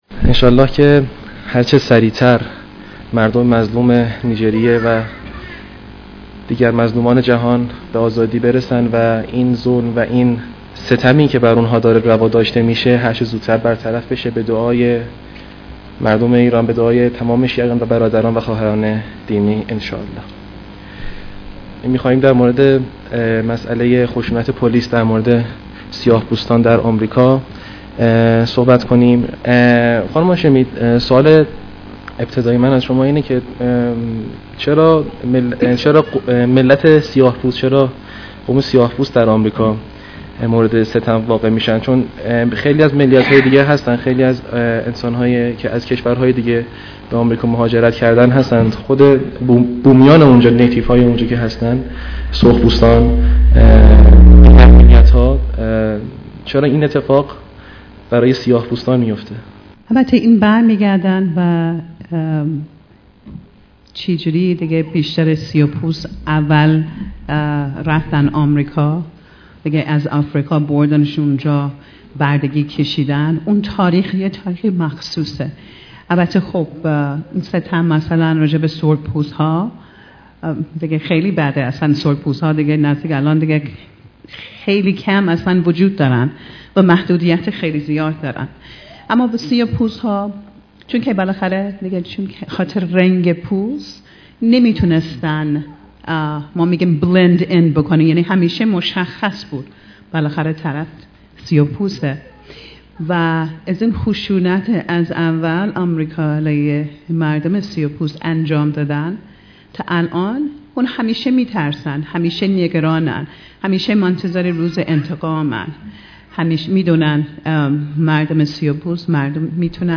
درسالن همایشهای خانه بیداری اسلامی